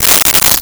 Coins In Hand 03
Coins in Hand 03.wav